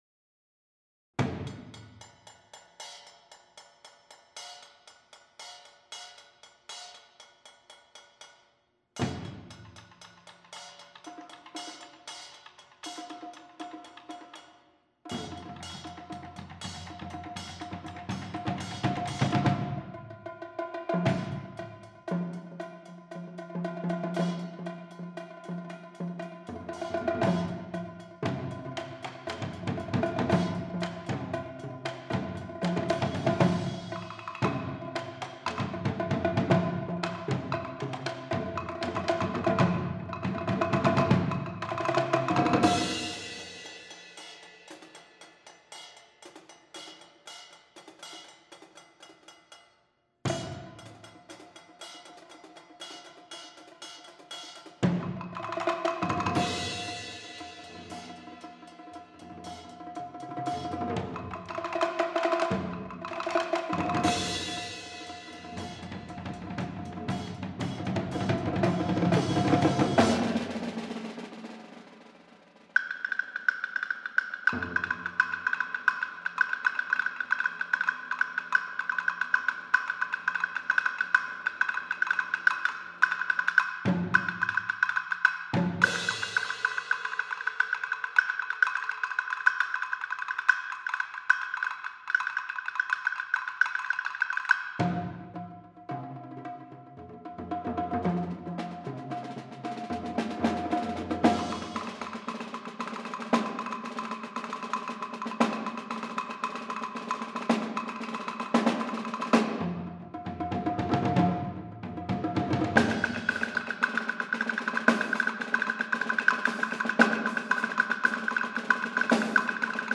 Genre: Percussion Ensemble
# of Players: 4
Player 1: Bongos, Medium Woodblock
Player 2: Small Cymbal, Low Woodblock
Player 3: Snare Drum, Kick Drum on Stand, Crash Cymbal
Player 4: 3 Toms, Hi-Hat